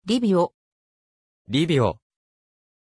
Aussprache von Lyvio
pronunciation-lyvio-ja.mp3